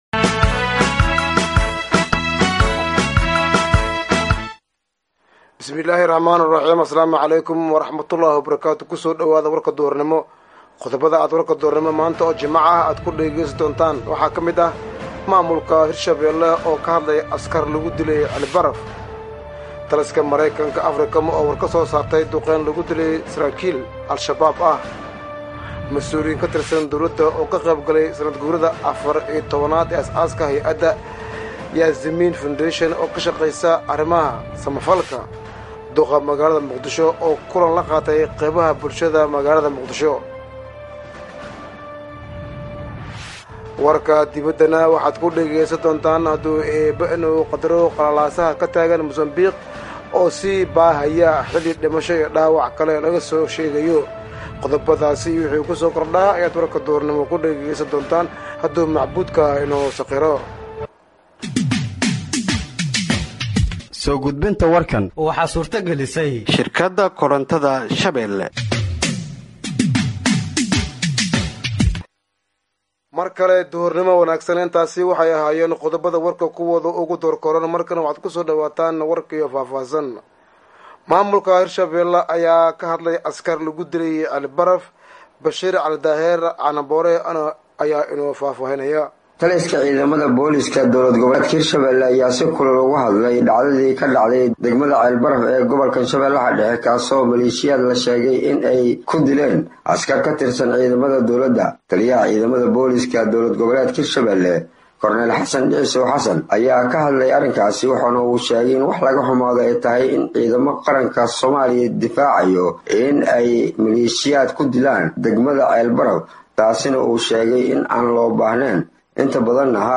Dhageeyso Warka Duhurnimo ee Radiojowhar 27/12/2024